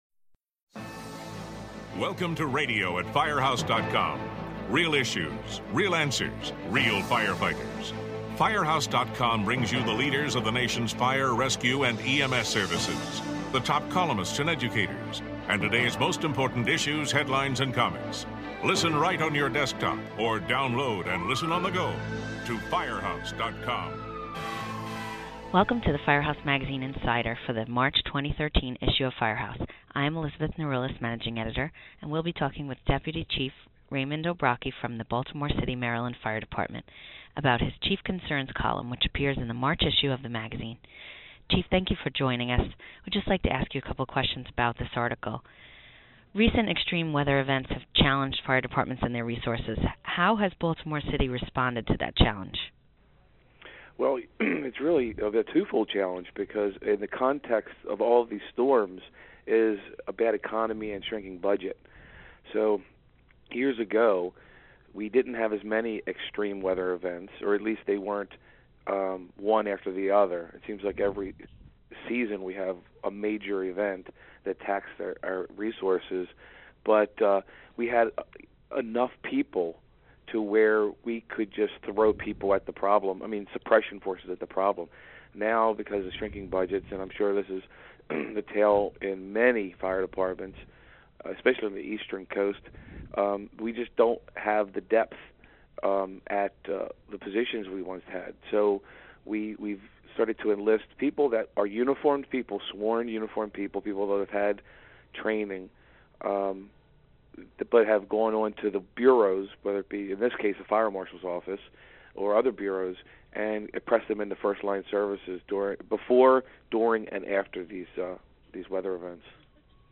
Listen as the two discuss how Baltimore City, MD, Fire Dept. handles emergency calls during extreme weather events.